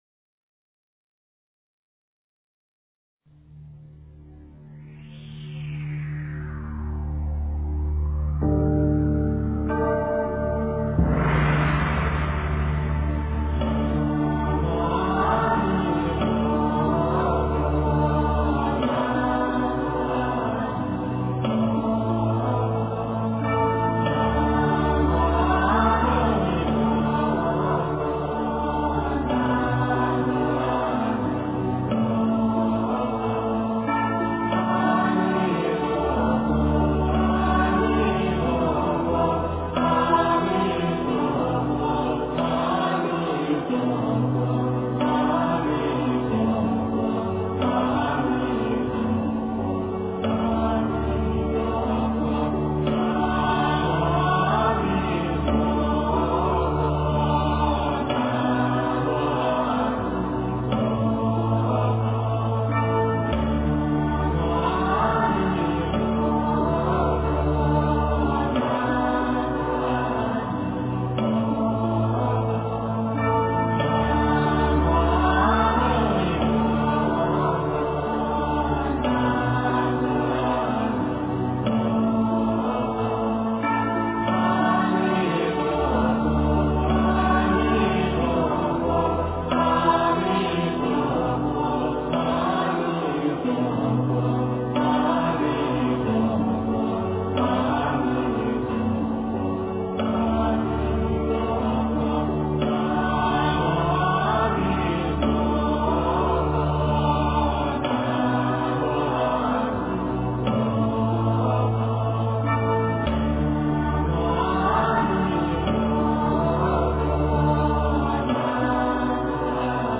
南无阿弥陀佛--中国佛学院法师 经忏 南无阿弥陀佛--中国佛学院法师 点我： 标签: 佛音 经忏 佛教音乐 返回列表 上一篇： 八十八佛大忏悔文-闽南语--圆光佛学院众法师 下一篇： 南无本师释迦牟尼佛--中国佛学院法师 相关文章 大乘金刚般若宝忏法卷上--金光明寺 大乘金刚般若宝忏法卷上--金光明寺...